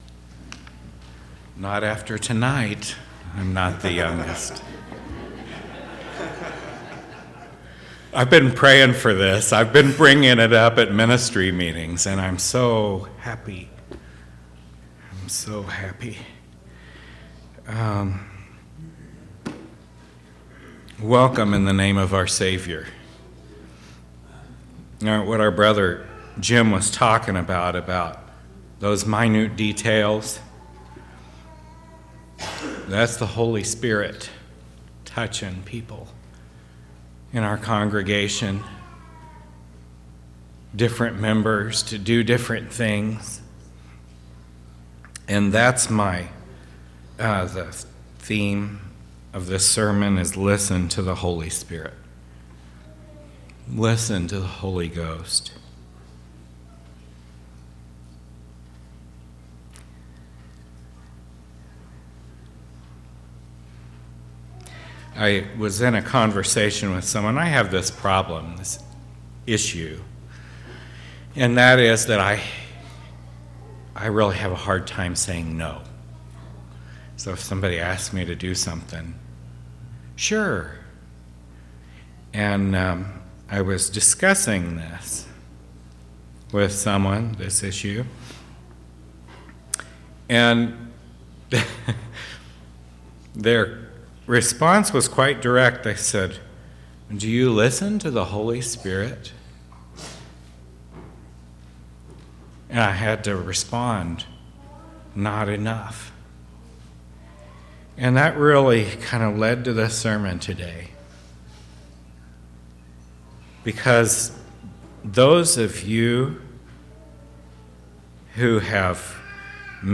10/30/2016 Location: Temple Lot Local Event